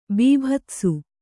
♪ bībhatsu